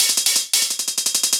Index of /musicradar/ultimate-hihat-samples/170bpm
UHH_ElectroHatB_170-03.wav